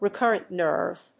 recurrent+nerve.mp3